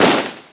M1 GARAND RIFLE
Semiautomatic
garand.wav